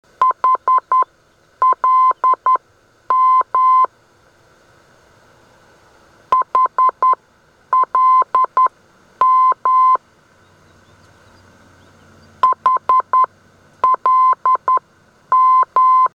HLM - Holland heard on 233 kHz: (253 kb)